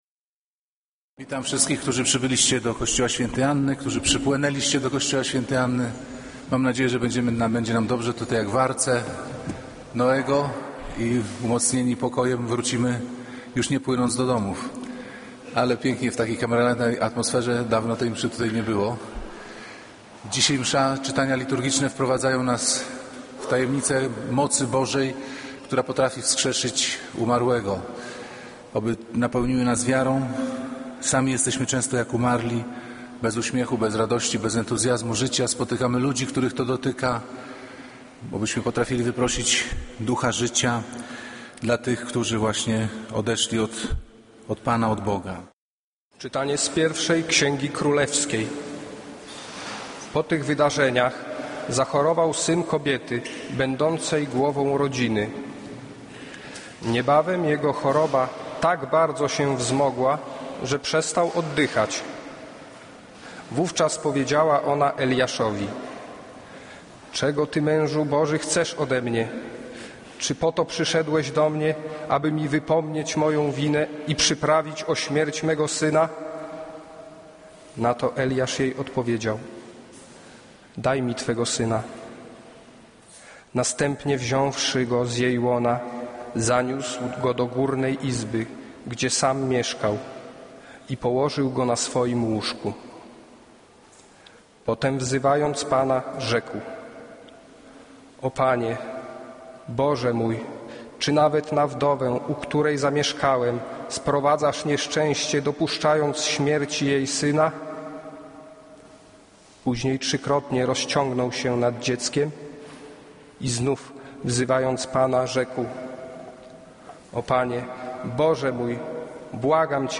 Kazanie z 9 czerwca 2013r.